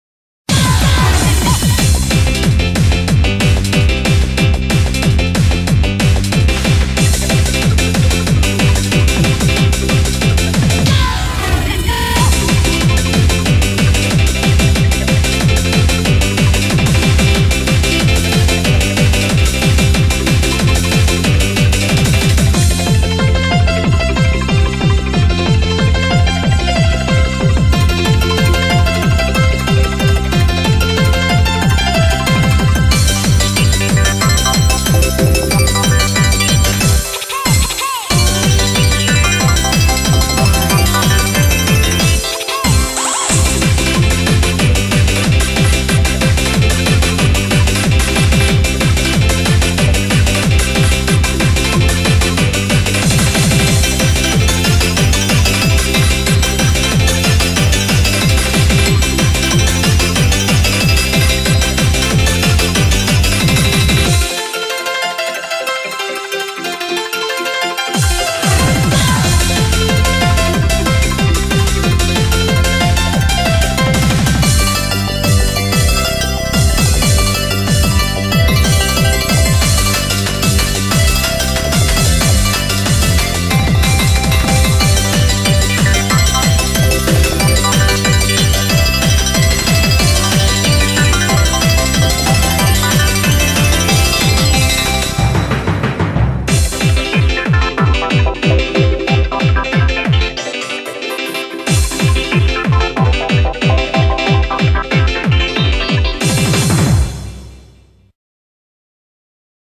BPM185
Comments[HAPPY HARDCORE]